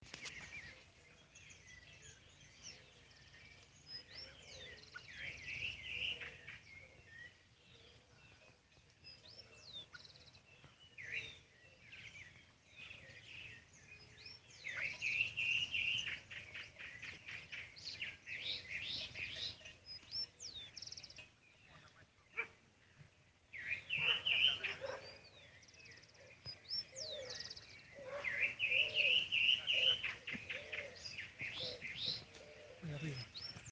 Bluish-grey Saltator (Saltator coerulescens)
Una vocalización poco grababa de este Pepitero
Life Stage: Adult
Location or protected area: Vuelta de Obligado (San Pedro)
Condition: Wild
Certainty: Recorded vocal
Pepitero-Gris.mp3